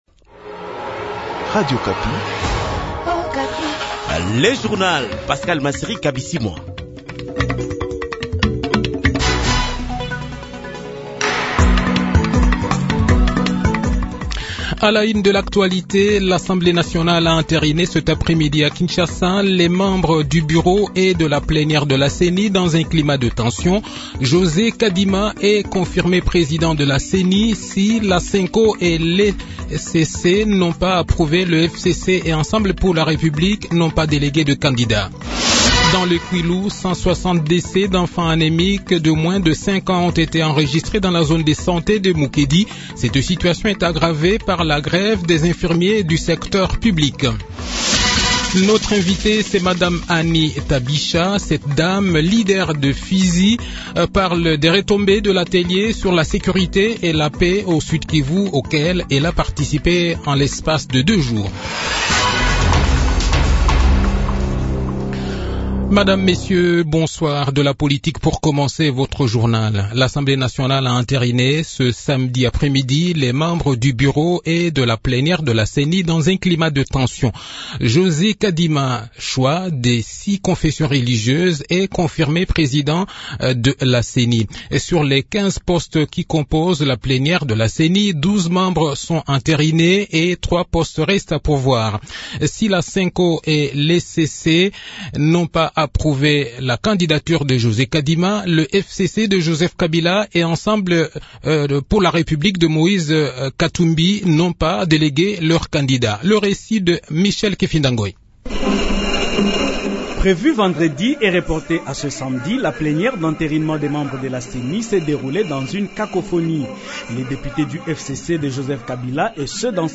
Le journal de 18 h, 16 Octobre 2021